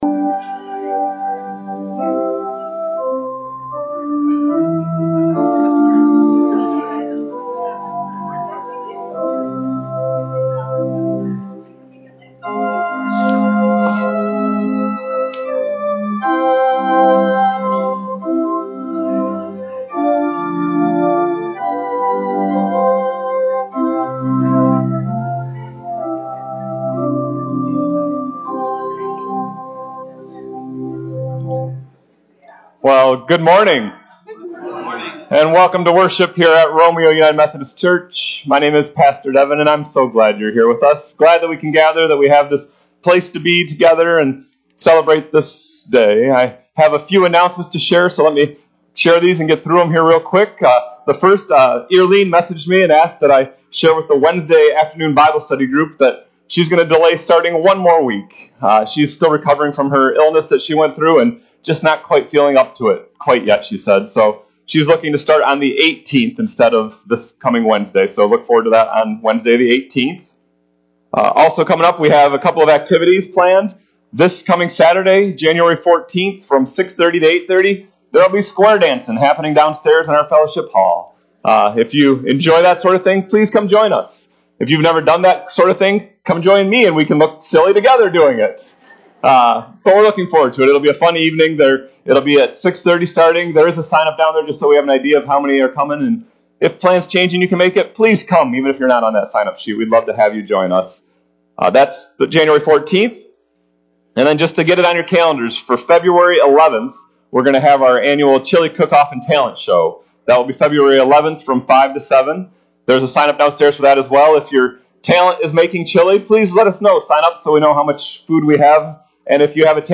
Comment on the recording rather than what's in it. RUMC-service-Jan-8-2023-CD.mp3